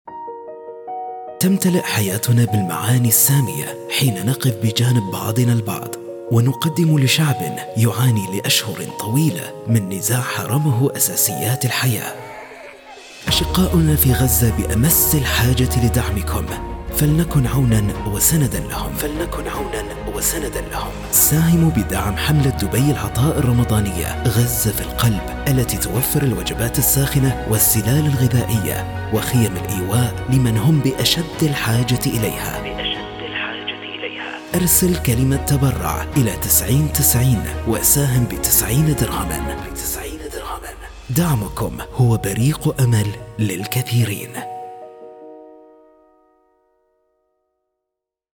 Index of /work/ENBD/VO-OPTIONS-FOR-THE-VIDEO/Arabic/Male/